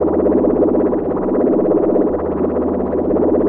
arcaneloop02.wav